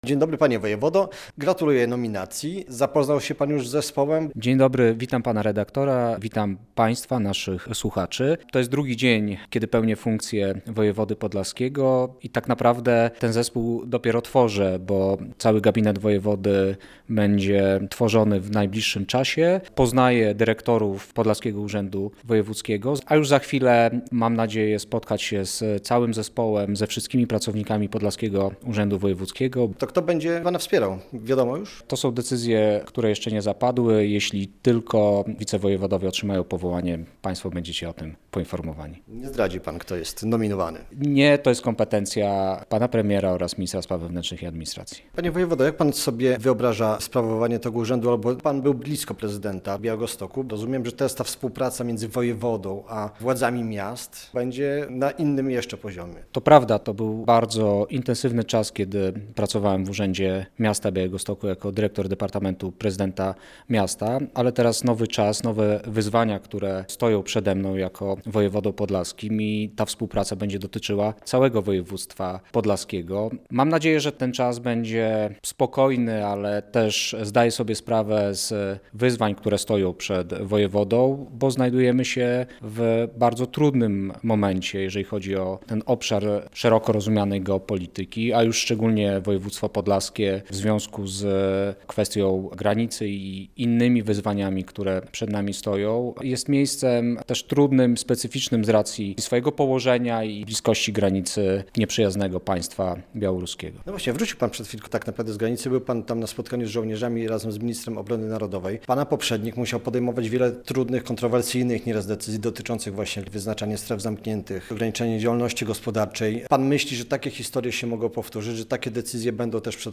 Radio Białystok | Gość | Jacek Brzozowski - wojewoda podlaski